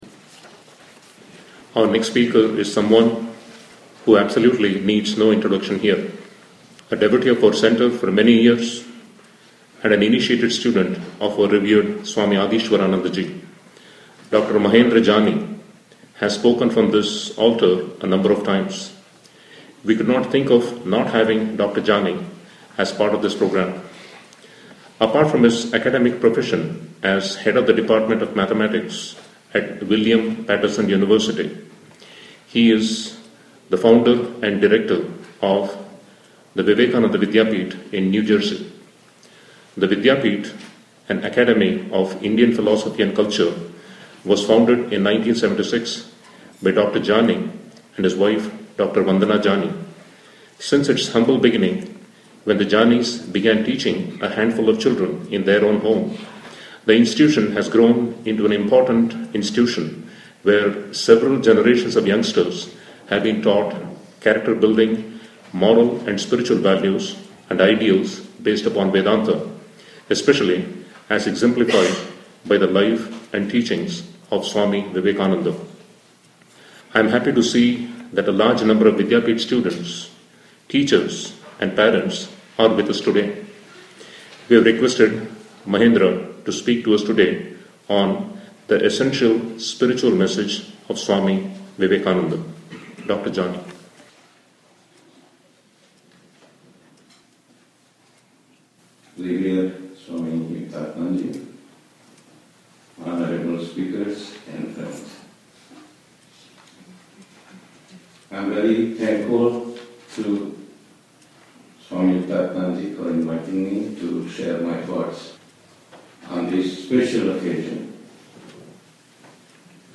Audio of talk